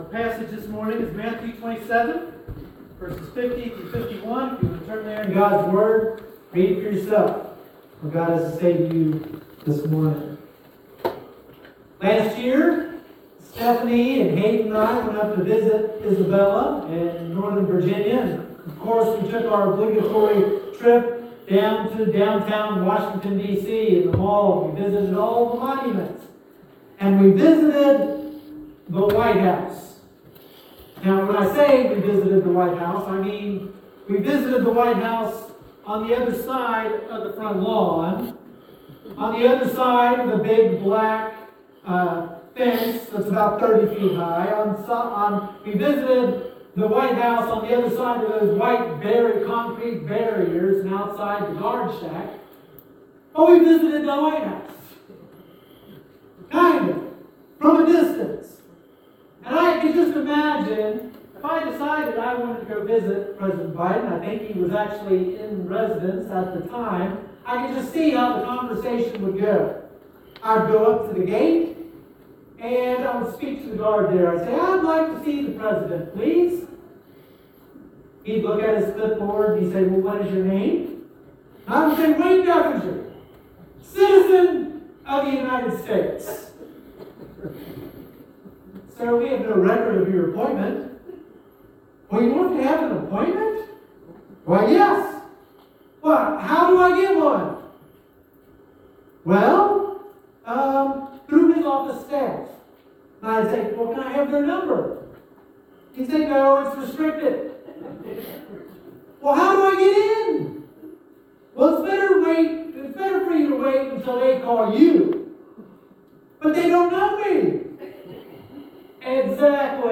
Sermons | Flint Hill Baptist Church